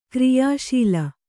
♪ kriyā śila